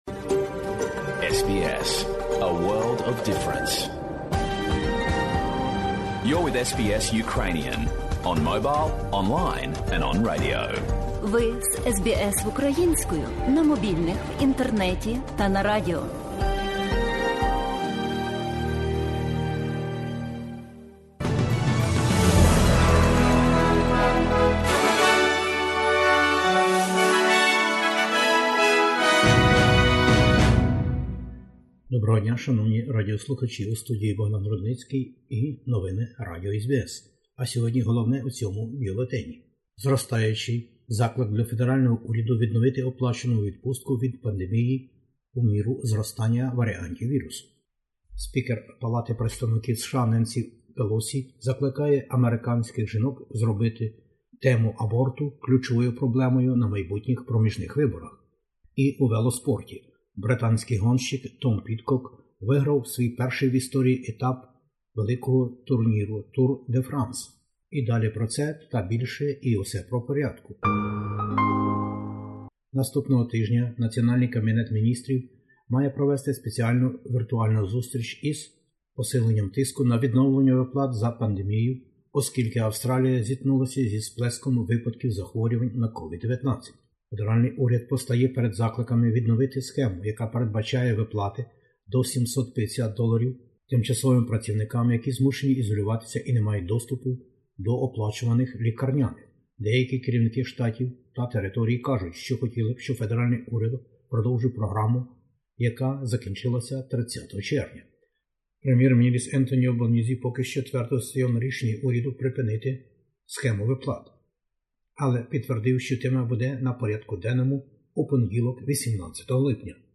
Бюлетень SBS новин українською мовою. Всенаціональний кабінет міністрів збереться 18 липня - вірус і оплата до $750 для низькооплачуваних і тих, котрі не мають право на лікарняні оплати від роботодавців.